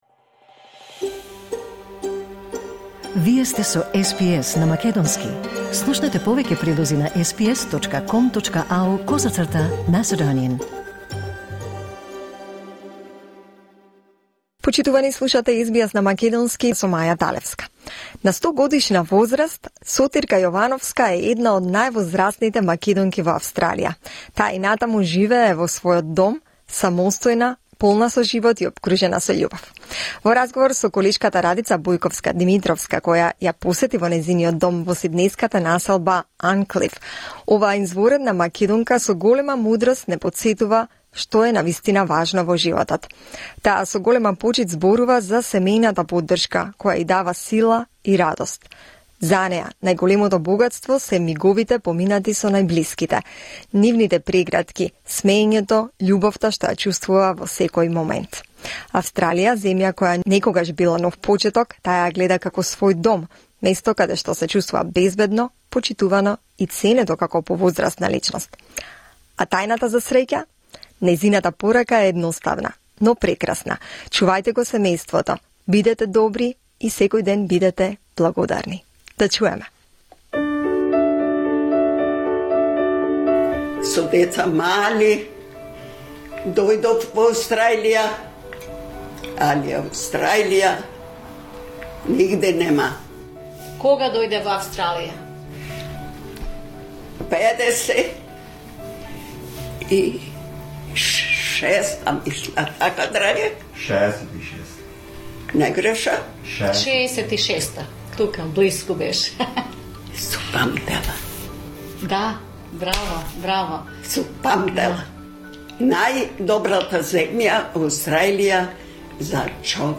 Tune in to this interview, filled with warmth, wisdom, and timeless life lessons.